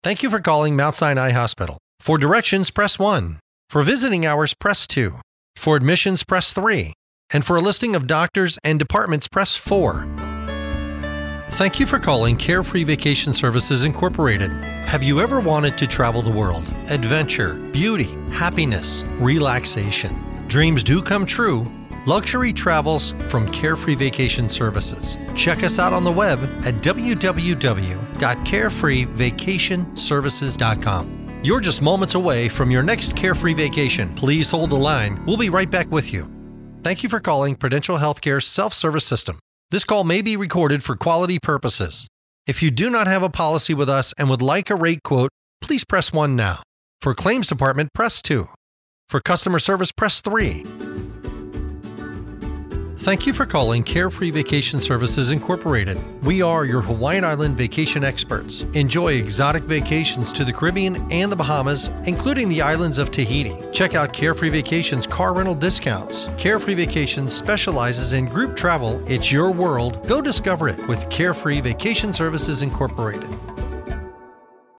Friendly, Warm, Conversational.
IVR, Phones